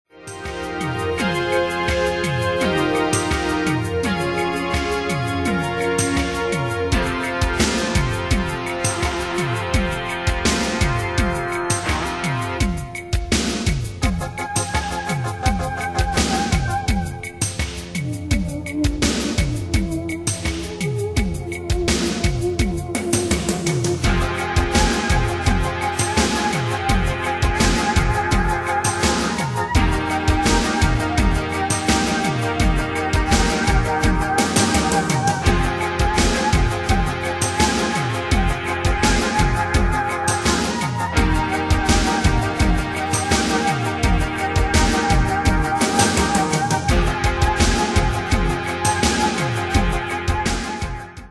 Style: Rock Année/Year: 1983 Tempo: 84 Durée/Time: 7.01
Danse/Dance: SlowRock Cat Id.
Pro Backing Tracks